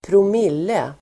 Ladda ner uttalet
promille substantiv, per thousand (mille)Uttal: [²prom'il:e] Böjningar: promillenDefinition: tusendelSammansättningar: promille|halt (content in parts per mille)